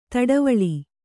♪ taḍavaḷi